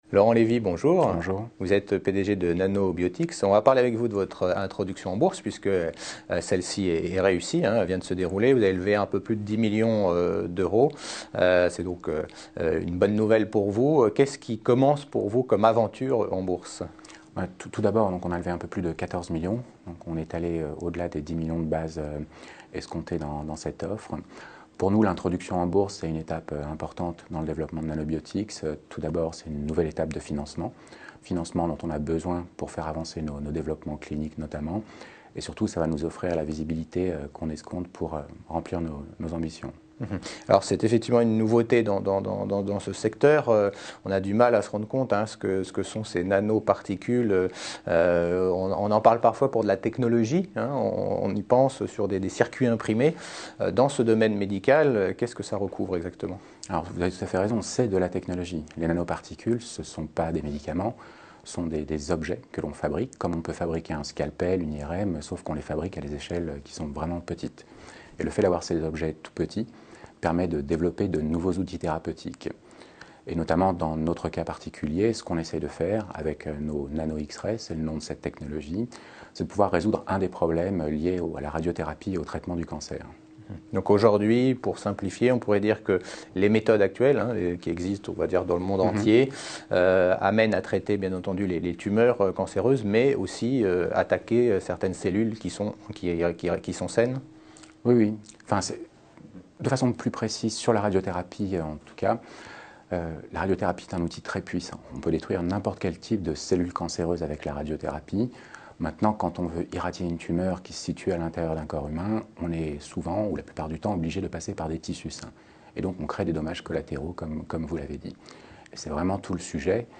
Introduction en Bourse : Interview